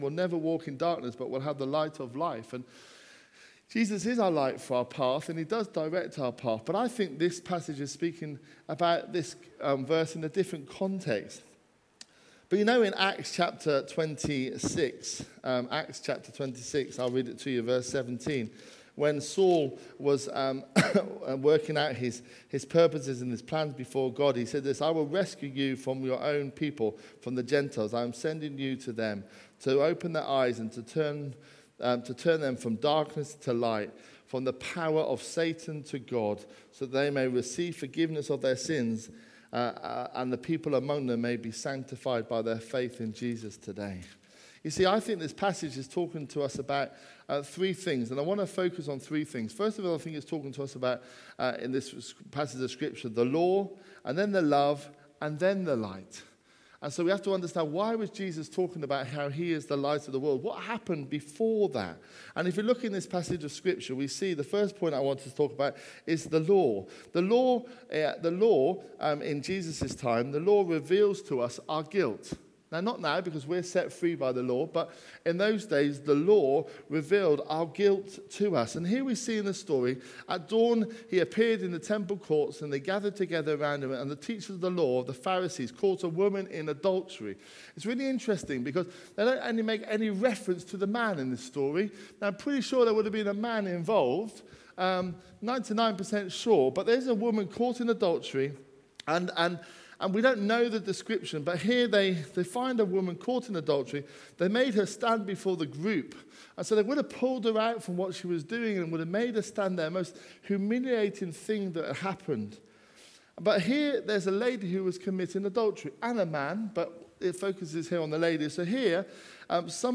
A message from the series "Jesus said 'I AM'."